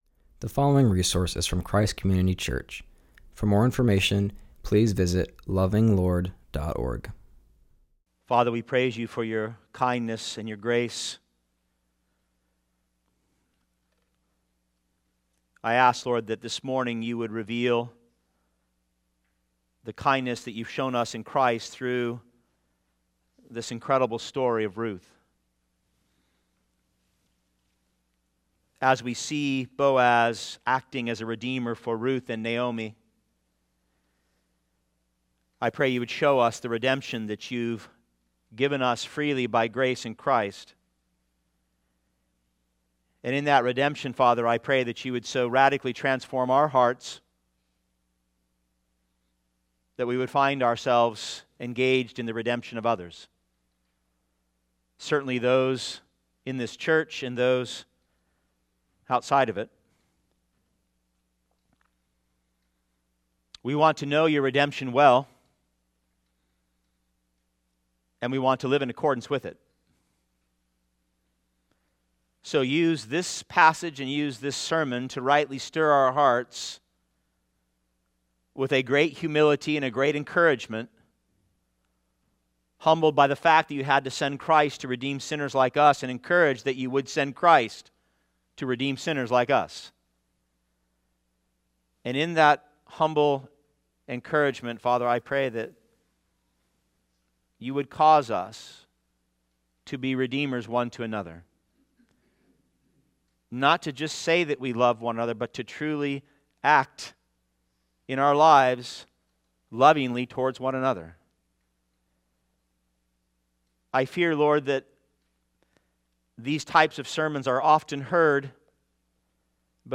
preaches on Ruth 2:14-23.